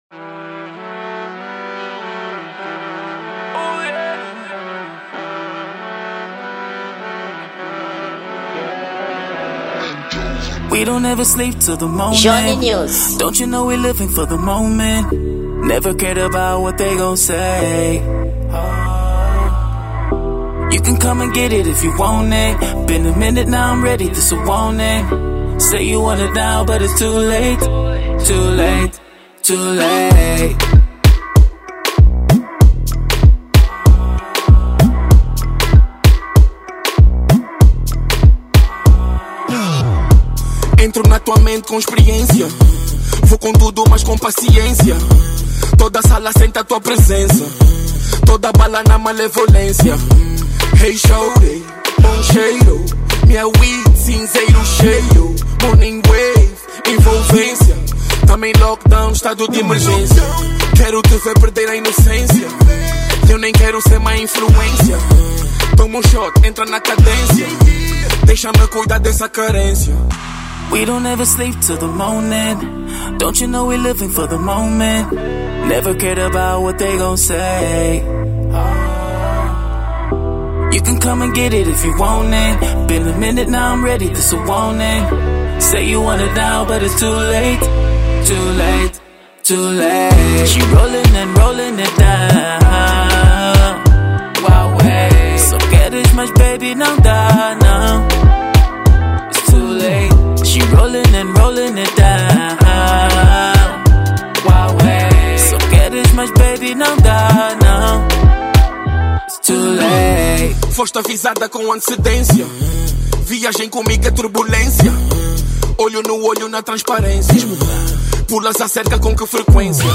Gênero: Tarraxinha